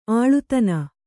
♪ āḷutana